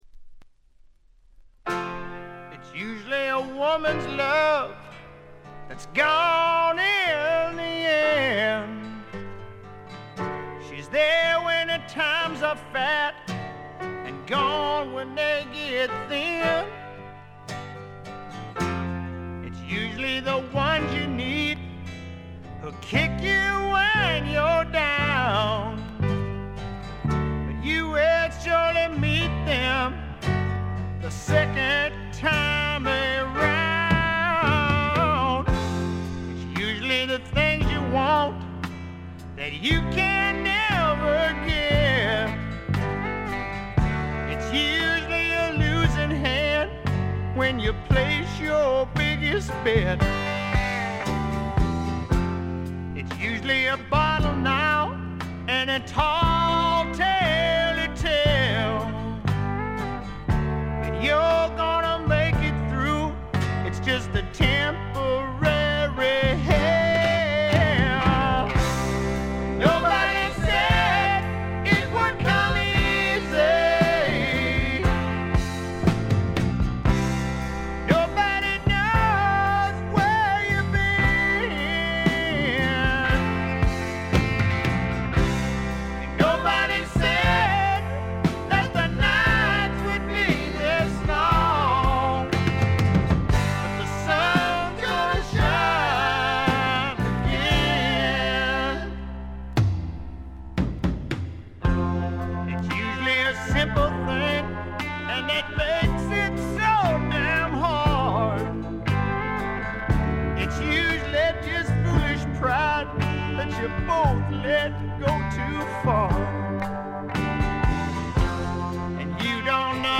部分試聴ですがほとんどノイズ感無し。
70年代末期にあって、あっぱれなスワンプ魂（ザ・バンド魂）を見せてくれました。
試聴曲は現品からの取り込み音源です。